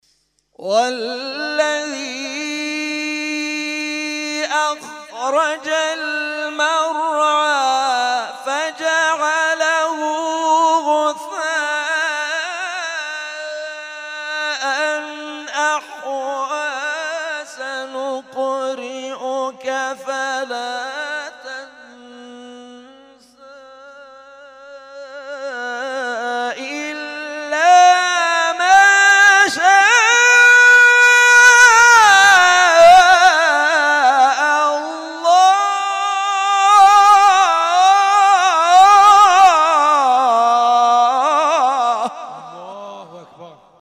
محفل انس با قرآن در آستان عبدالعظیم(ع)
قطعات تلاوت